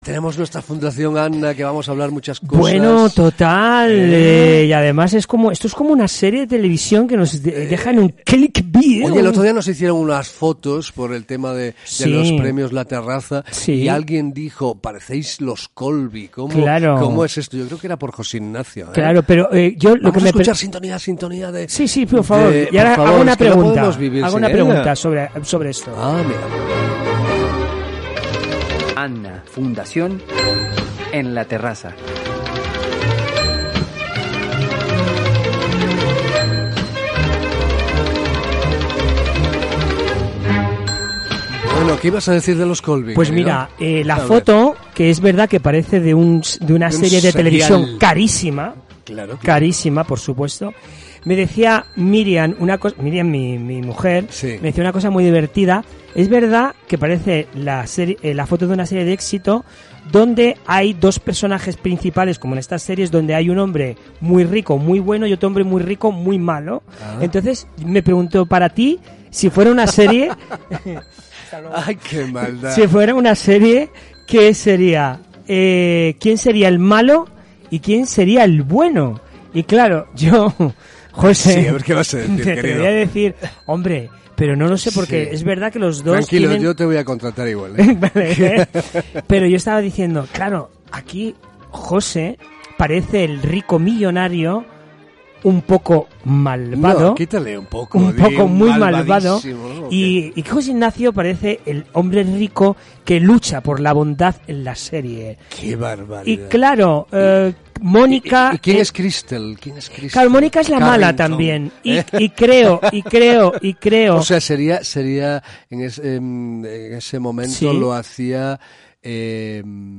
AN-A FUNDACIÓN EN RADIO INTERCONTINENTAL MADRID 95.4 FM hoy jueves 6.2.2025